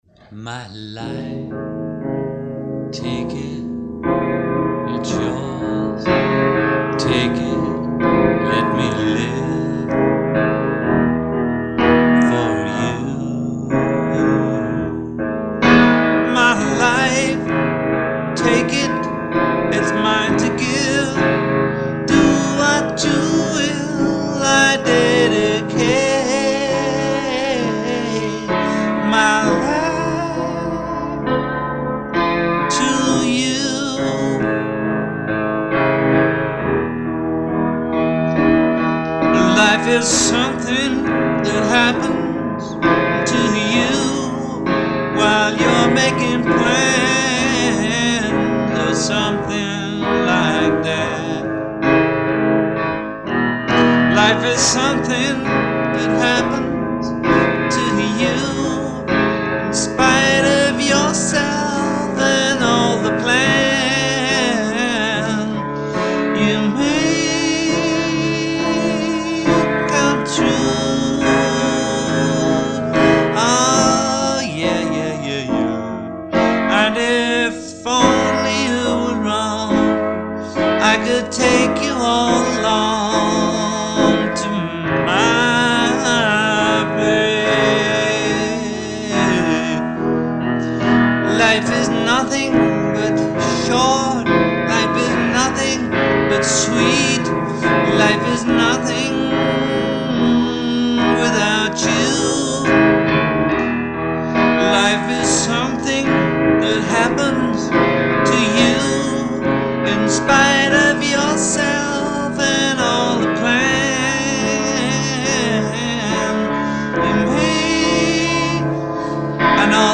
Disc 2 - Piano demos